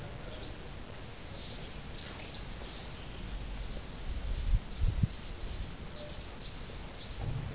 Abb. 11: Mauersegler, sehr hoher Frequenzbereich, Auswertekapazität  reicht leider nur bis 5 kHz.
(da Aufnahme mit 11 kSamples)